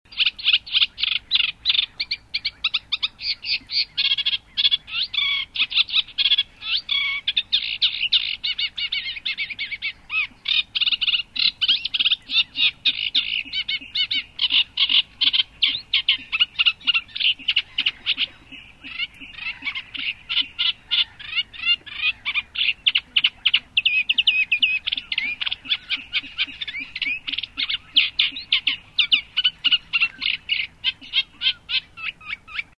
, a cserregő nádiposzáta (Acrocephalus scirpaceus)
, amelynek dala a nádirigóéra emlékeztet (tiri-tiri-tir-tir).